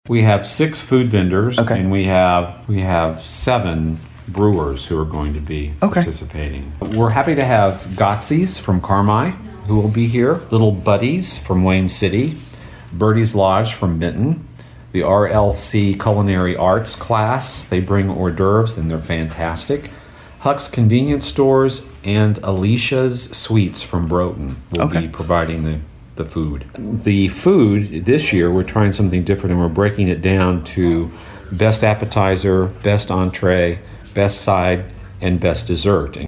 Fred Vallowe, McLeansboro City Clerk, was a guest on WROY’s Open Line program recently and explained how the event came about…